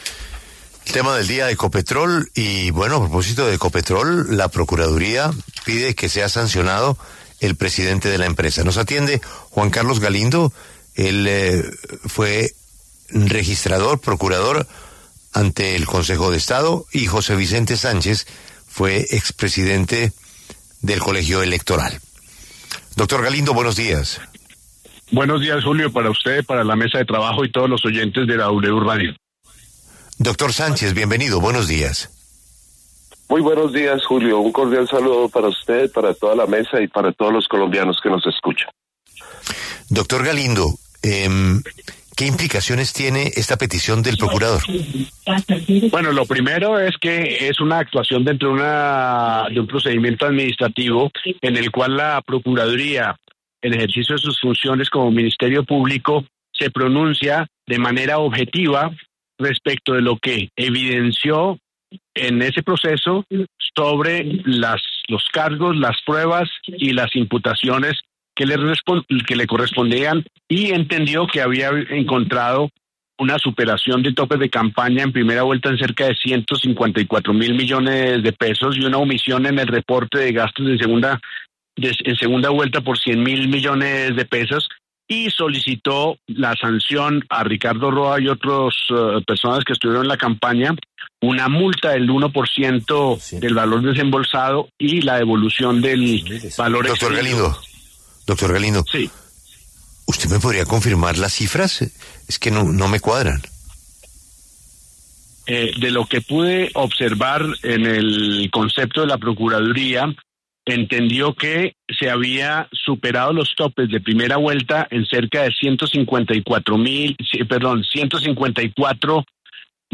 En entrevista con La W